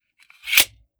fps_project_1/45 ACP 1911 Pistol - Magazine Load 001.wav at 3e46060a70848c52f8541c7d1ccb36af7950df5e